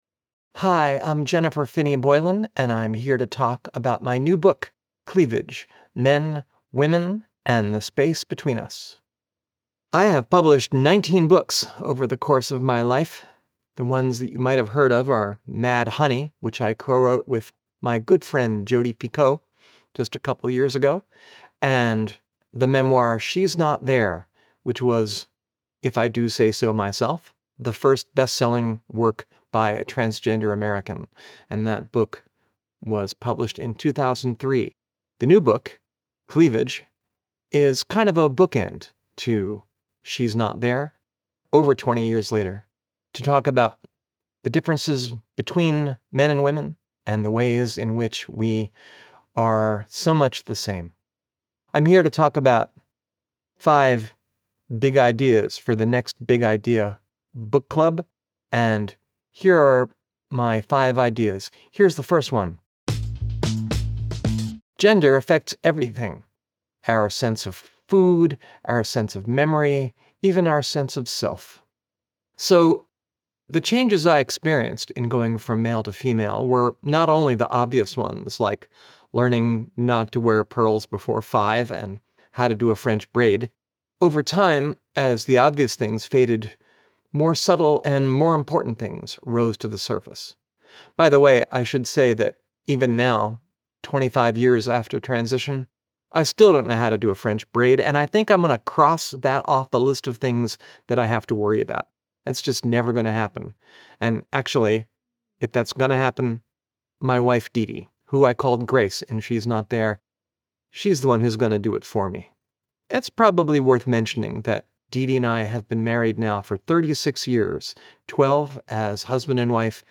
Listen to the audio version—read by Jennifer herself—in the Next Big Idea App.